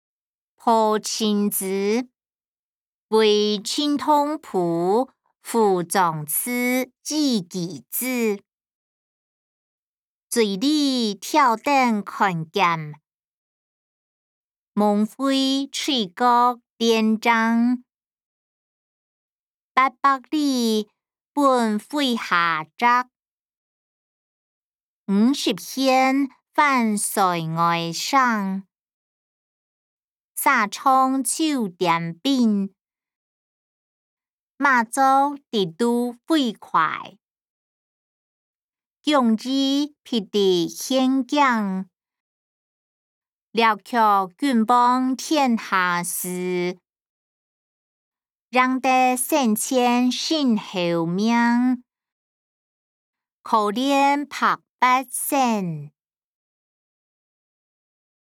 詞、曲-破陣子•為陳同甫賦壯詞以寄之音檔(海陸腔)